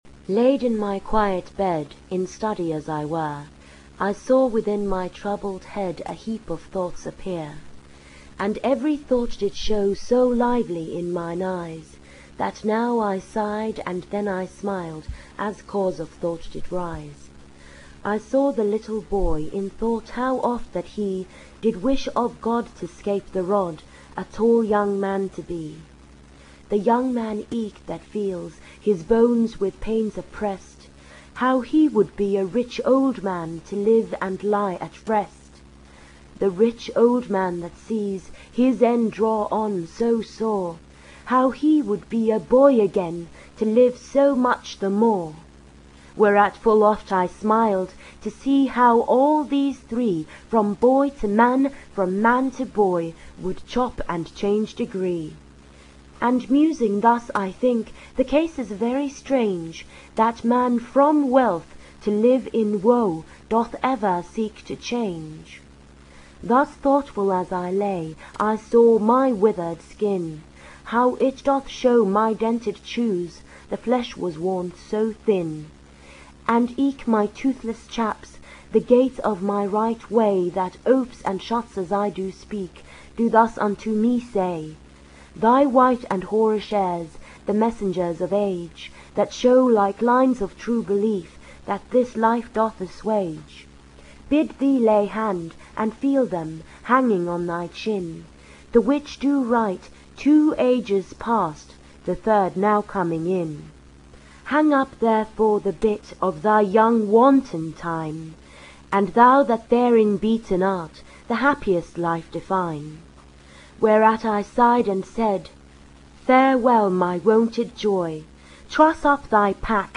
Audio reading